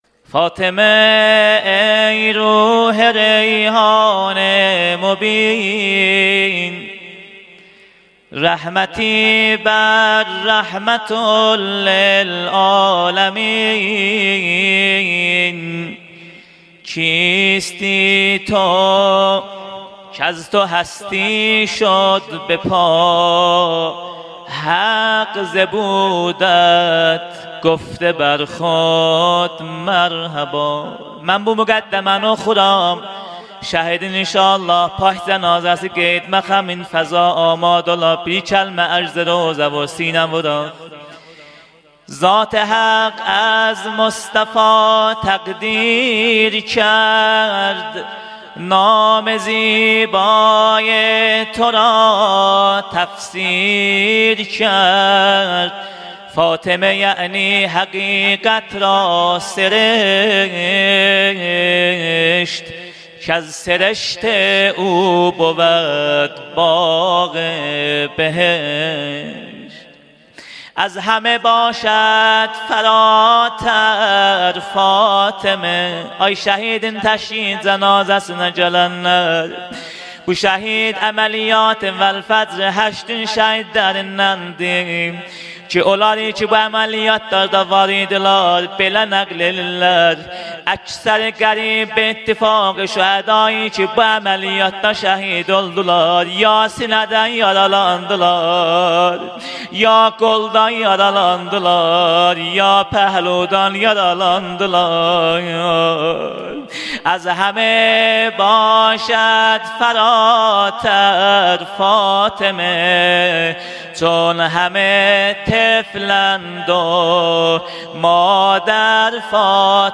مقدمه و روضه
فاطمیه۹۱